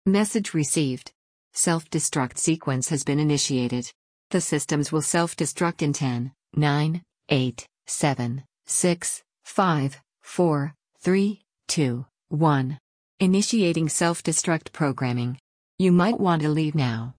self_destruct.mp3